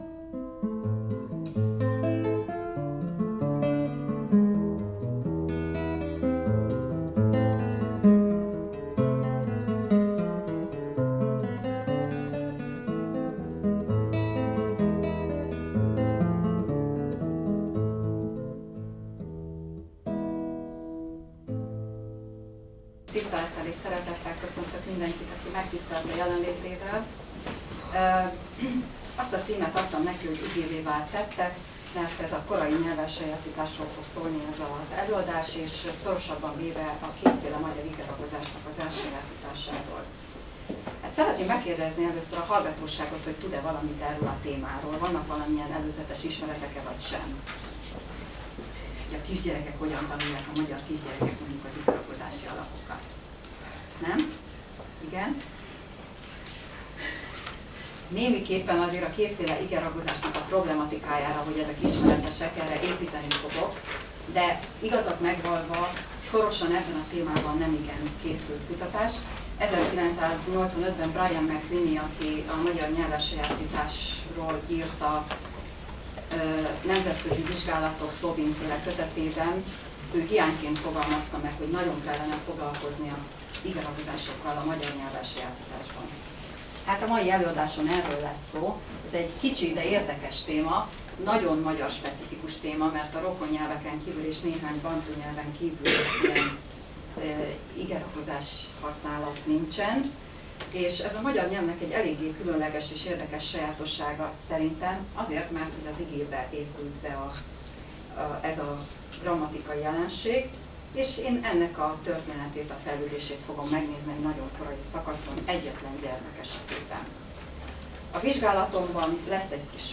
Csatornák A Társas-Kognitív Nyelvészeti Kutatócsoport 2013. évi tavaszi előadás-sorozata